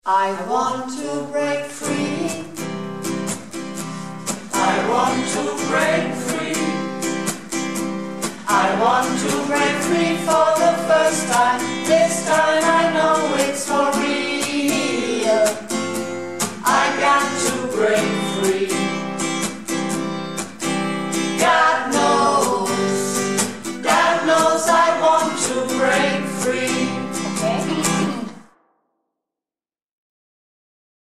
Projektchor "Keine Wahl ist keine Wahl" - Probe am 21.05.19
I Want To Break Free (Stimme 1)